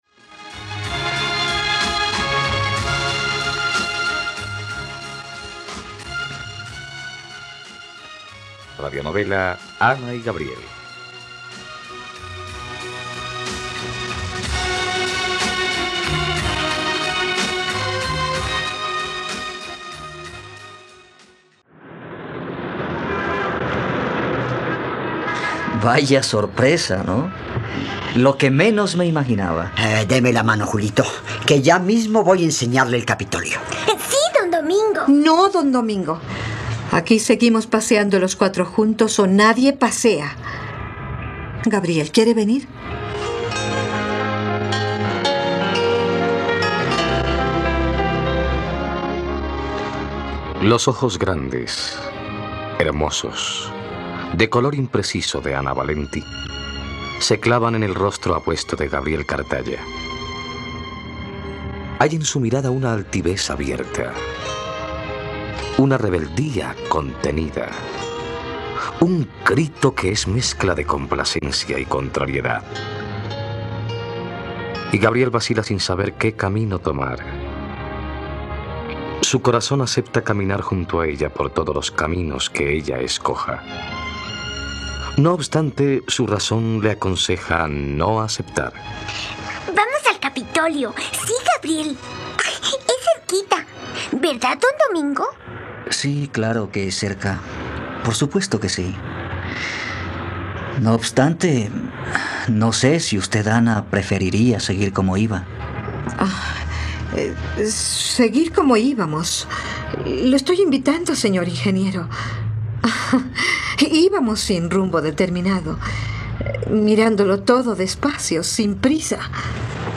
..Radionovela. Escucha ahora el capítulo 34 de la historia de amor de Ana y Gabriel en la plataforma de streaming de los colombianos: RTVCPlay.